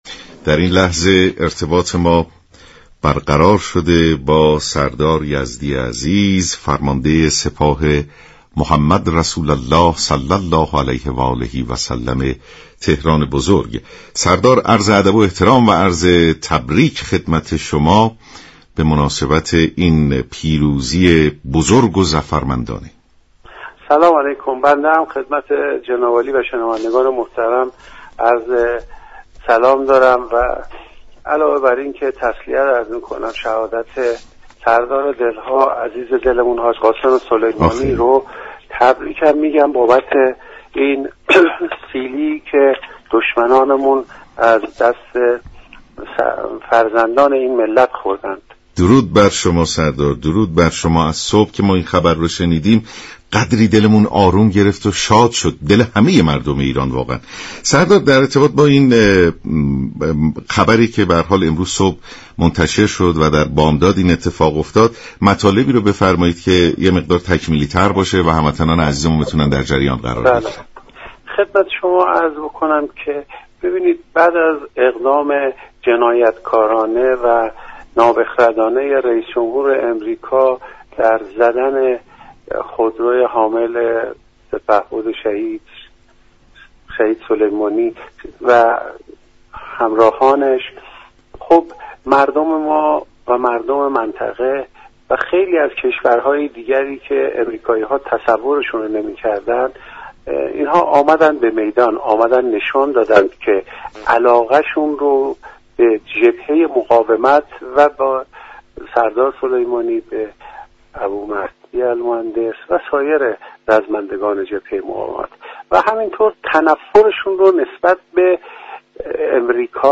به گزارش شبكه رادیویی ایران، سردار یزدی فرمانده سپاه محمد رسول (ص) تهران بزرگ در گفت و گو با رادیو ایران گفت: پس از اقدام جنایتكارانه و نابخردانه رییس جمهور آمریكا در حمله به خودروی حامل سپهبد شهید حاج قاسم سلیمانی و همرزمانش، مردم ایران، منطقه و دیگر كشورها، علاقمندی خود را به جبهه مقاومت نشان دادند.